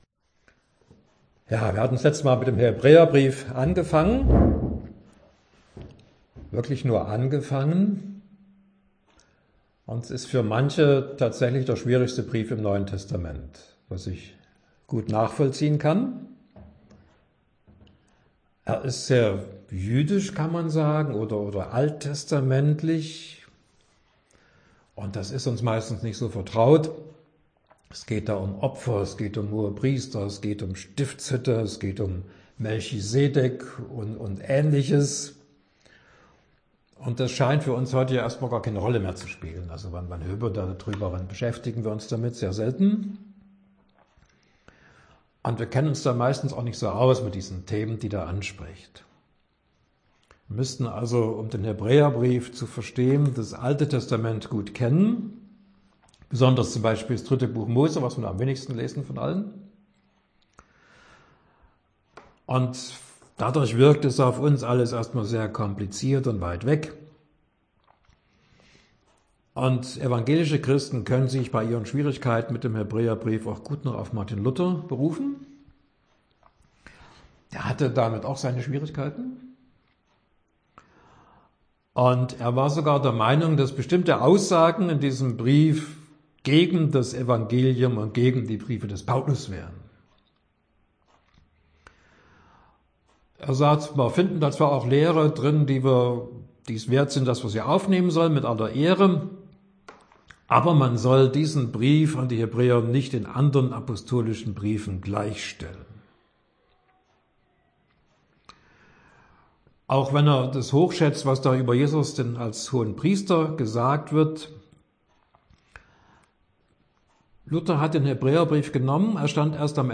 Vorträge